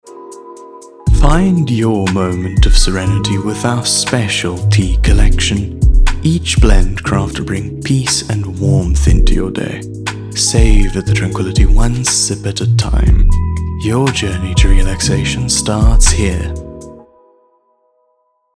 animation, articulate, authoritative, character, Deep
Soft Sell- Tea Collection